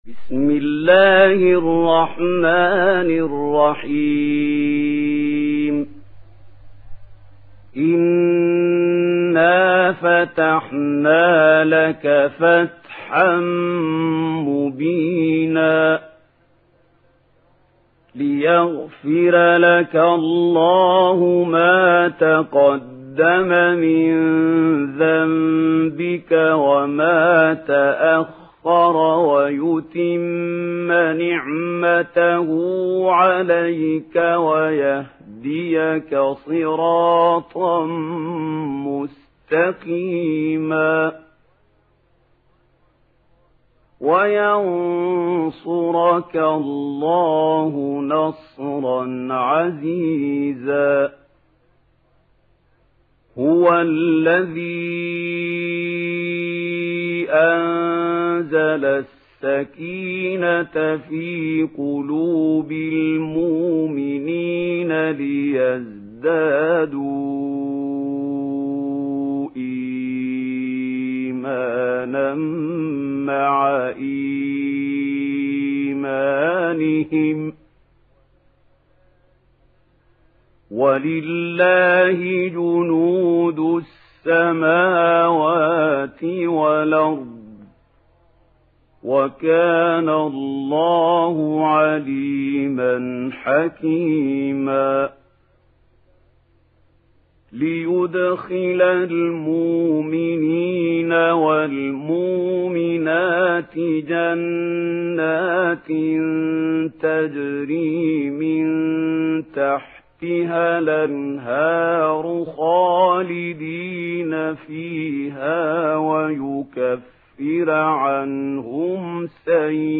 Fetih Suresi İndir mp3 Mahmoud Khalil Al Hussary Riwayat Warsh an Nafi, Kurani indirin ve mp3 tam doğrudan bağlantılar dinle